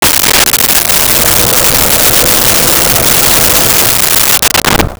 Bathroom Fan
Bathroom Fan.wav